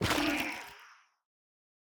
Minecraft Version Minecraft Version 1.21.5 Latest Release | Latest Snapshot 1.21.5 / assets / minecraft / sounds / block / sculk_sensor / break3.ogg Compare With Compare With Latest Release | Latest Snapshot
break3.ogg